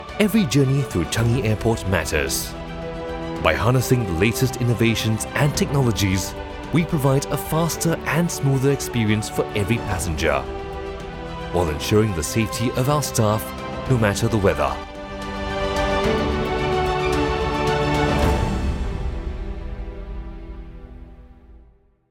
Male
English (Neutral - Mid Trans Atlantic)
A warm, clear, and authoritative Singaporean voice.
My tone ranges from a friendly, conversational guy-next-door to a highly trusted, serious professional.
I deliver broadcast-quality audio with excellent diction and pacing.